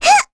Lilia-Vox_Attack1.wav